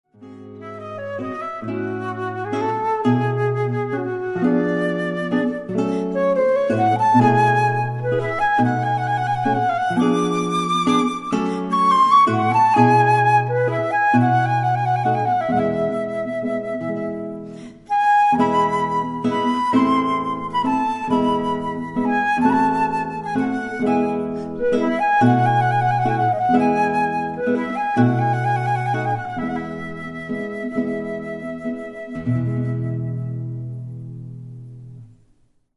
McKenzie Flute & Guitar Duo - Home